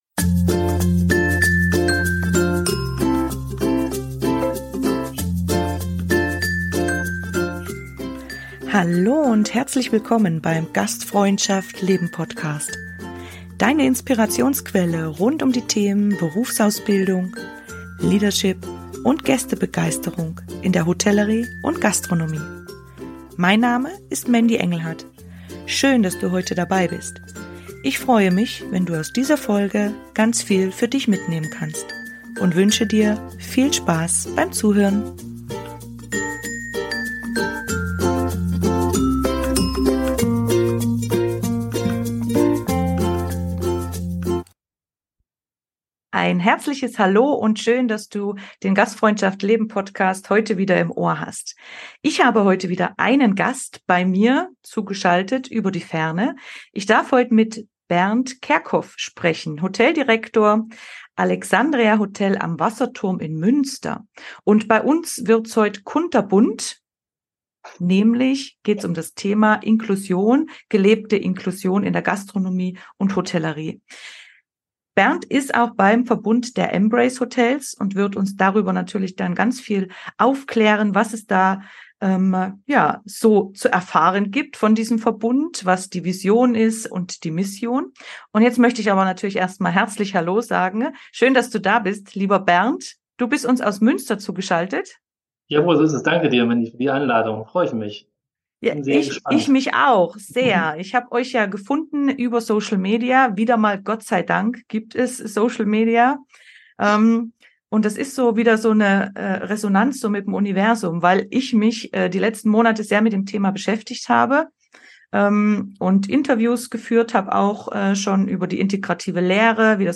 Du darfst dich auf ein wahnsinnig spannendes Interview mit jeder Menge Praxiserfahrungen und Input freuen. Einige Fragen, mit denen wir uns im ersten Teil befasst haben, sind: Warum ist Inklusion von Mitarbeitenden und Gästen in unserer Branche so wichtig?